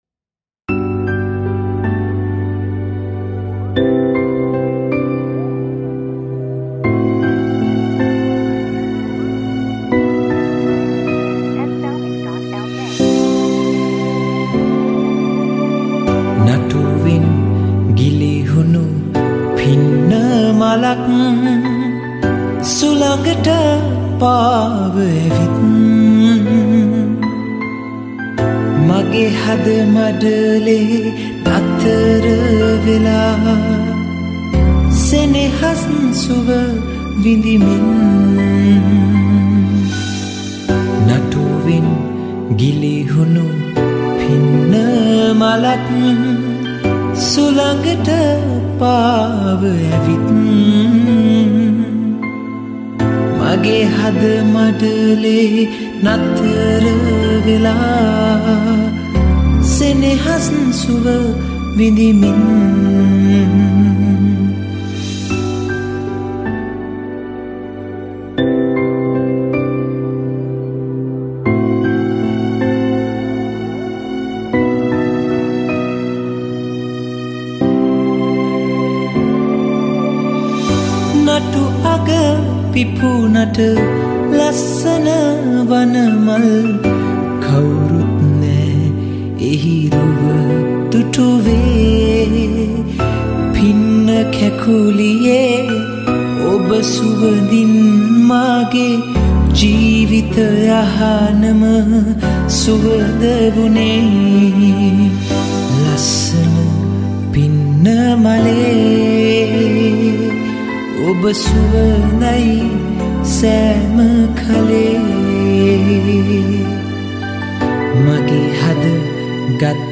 Covers